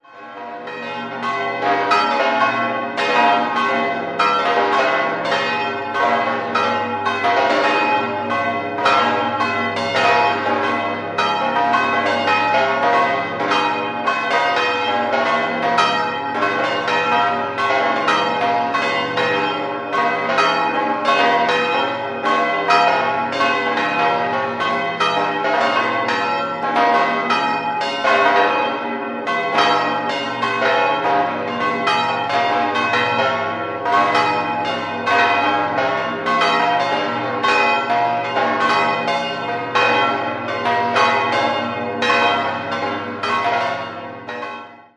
Hohenraunau, Friedhofsgeläute
Auf dem idyllischen Friedhof, am höchsten Punkt des Ortes, befindet sich ein absolutes Unikat, nämlich das größte freistehende Geläute Deutschlands. Es wurde aus sechs ausgedienten Gussstahlglocken zweier Kirchen zusammengefügt und erklingt jeden Samstag um 18:00 Uhr und zu besonderen Anlässen - vorausgesetzt es sind genügend Personen aus dem Ort zum Läuten anwesend, da die Glocken per Hand geläutet werden.
6-stimmiges Geläute: as°-ces'-des'-f'-as'-b'
Die drei kleinen Glocken in Sekundschlagrippe stammen aus der Pfarrkirche Niederraunau (Gesamtdisposition des'-f'-as'-b'), die drei größeren in einer sehr leichten Sonderversuchsrippe vom Turm der Kirche St. Sylvester in Hiltenfingen (Gesamtdisposition gis°-h°-cis'-e'-fis') .
bell
Ein kurioses Geläute, an dem man natürlich nicht die Maßstäbe anlegen darf, wie man es bei einem Geläute in einem Kirchturm tun würde.